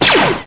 blaster.mp3